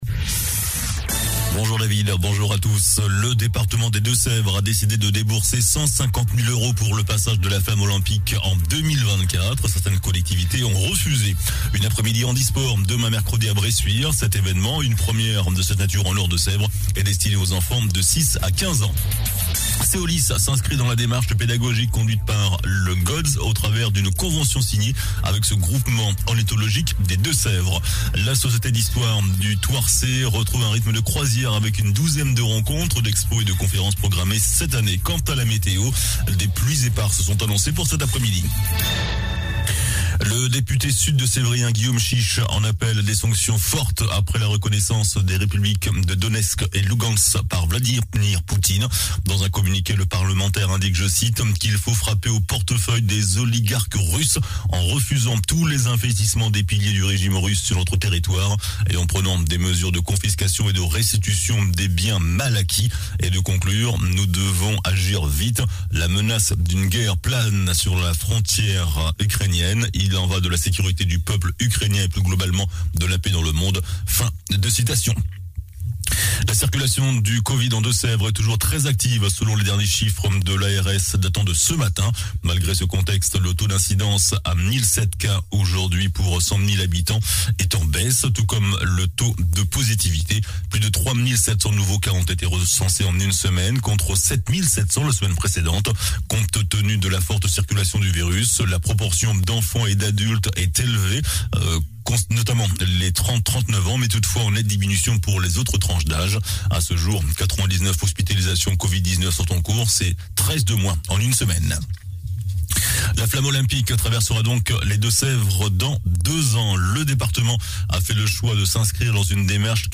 JOURNAL DU MARDI 22 FEVRIER ( MIDI )